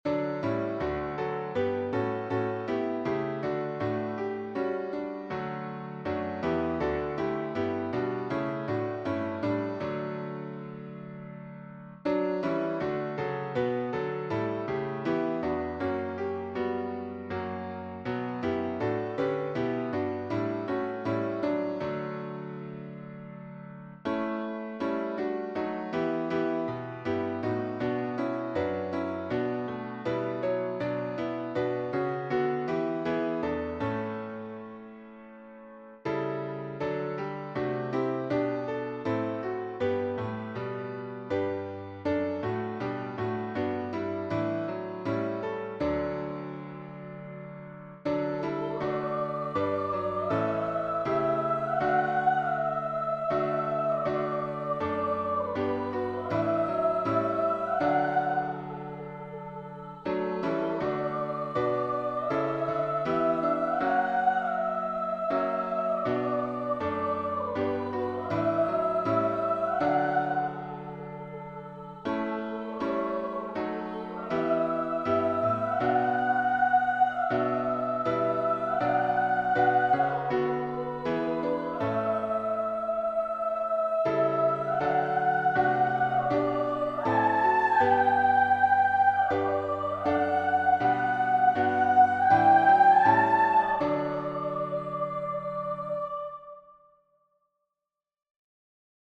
OChristtheWordIncarnate-LittleFreeMusicLibrary(pianosynth) (mp3)